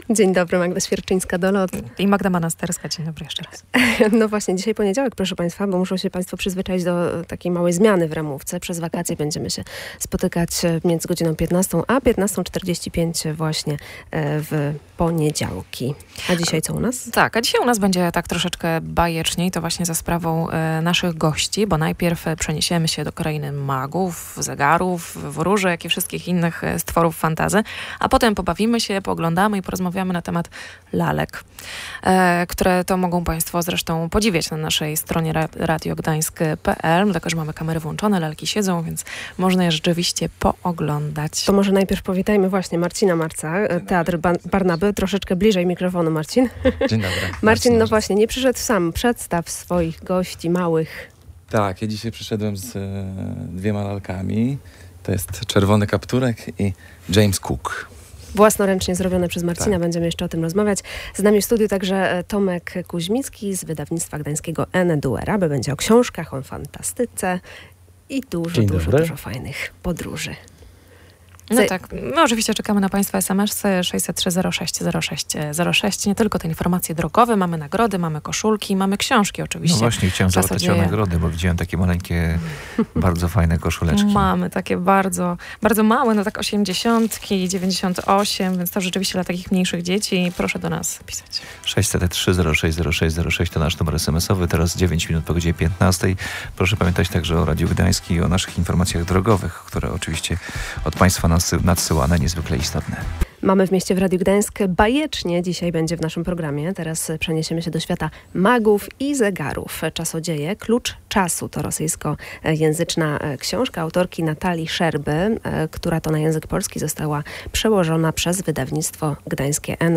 Laki na sam koniec nawet… przemówiły.